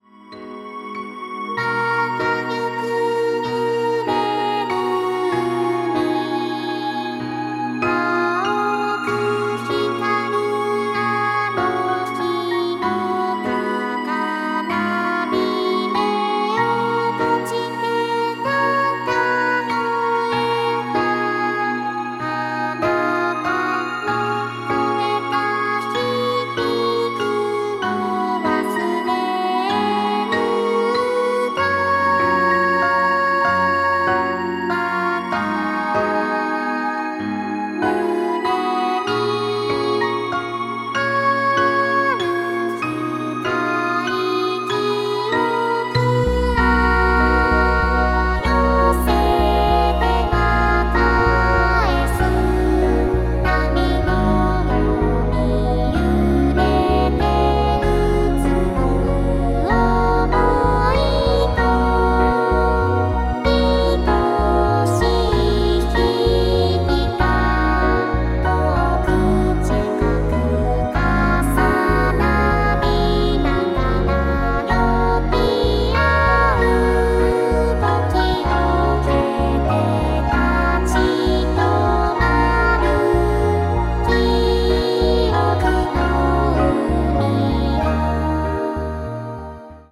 YAMAHAのVocaloidではなく全く新しい方式で初音ミクが歌うソフトウェア、初音ミクNT 1.0.0.1を試してみました。
全体的にVocaloidの時よりも音節の区切りが綺麗に接続されているのと、歌の歌詞が聴き取りやすくなっている印象です。1.0.0.0から1.0.0.1になり長い音符の末尾で自動的に(やや機械的ですが)フェードアウトするようになっています。ビブラートなどはまた未実装のようですが、とりあえず歌ってみる分には十分に実用的になってきました。 拙作の曲「記憶の海」をミクNTに歌わせてみました。